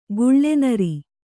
♪ guḷḷe nari